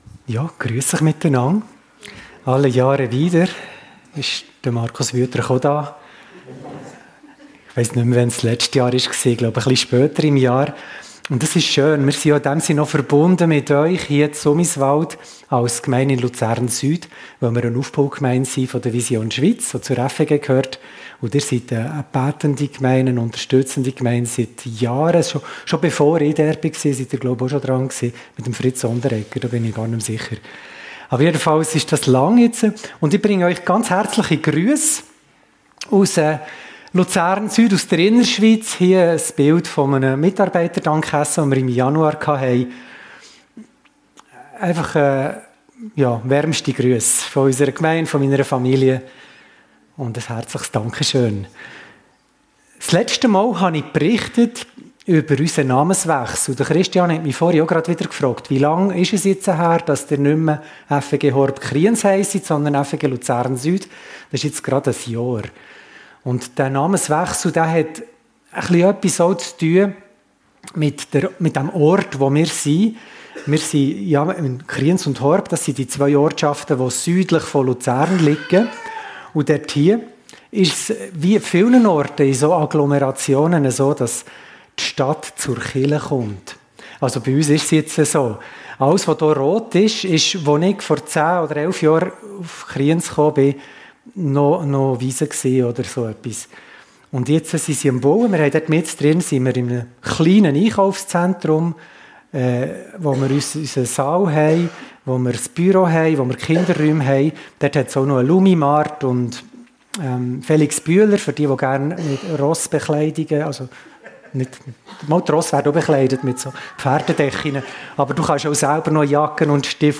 Predigt in der FEG Sumiswald am 9. Juni 2024 1. Thessalonicher 5, 11 Einander ermutigen, ermahnen und trösten